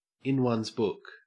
Ääntäminen
Ääntäminen AU Haettu sana löytyi näillä lähdekielillä: englanti Käännöksiä ei löytynyt valitulle kohdekielelle.